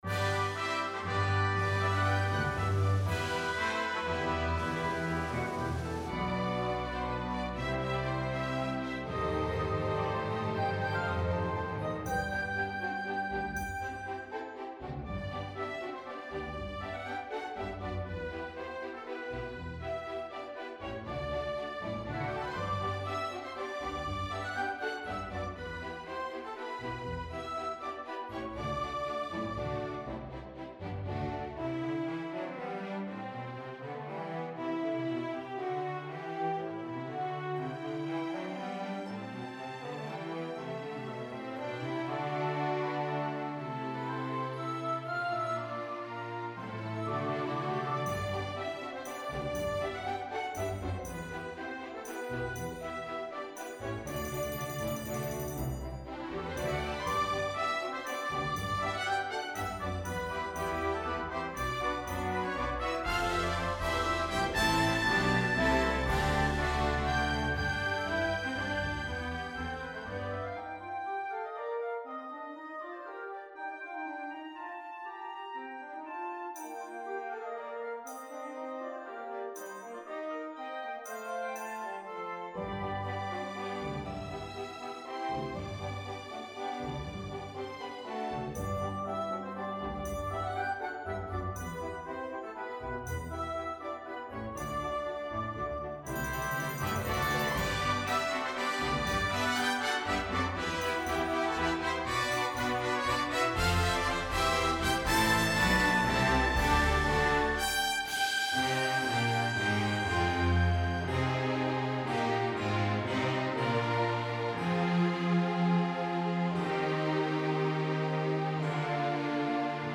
Original composition for orchestra